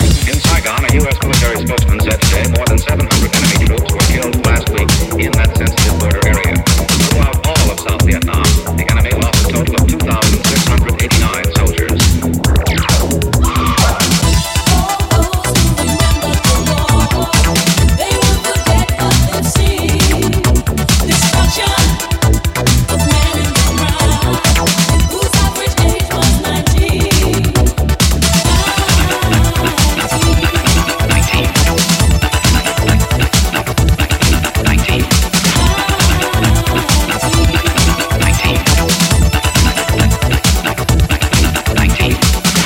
Genere: pop, rock, elettronica, successi, anni 80